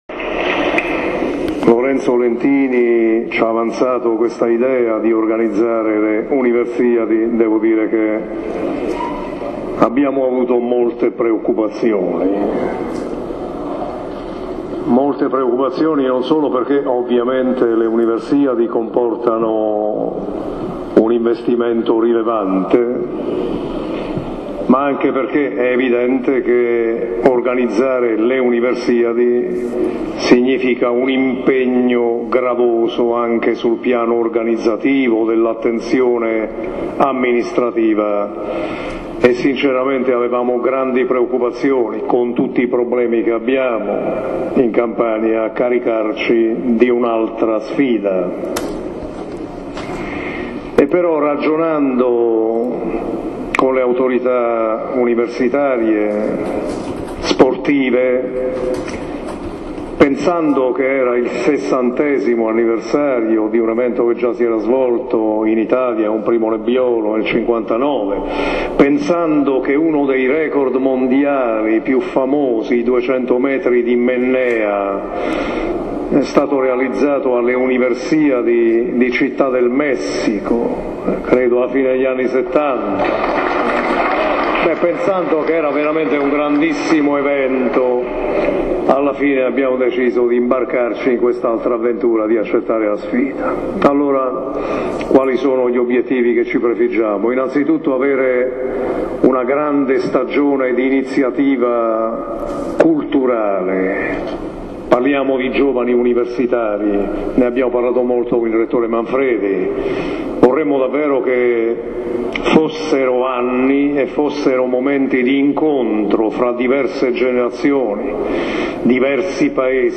Accogliendo la simbolica torcia proveniente dall’Università di Salerno, le cifre le ha svelate stasera De Luca  alla Stazione Marittima di Napoli: “170 Paesi, 15mila atleti, 5mila accompagnatori, le famiglie. 270 milioni di investimento per rifare gli impianti in Regione”.
Ascolta l’audio dell’intervento di De Luca.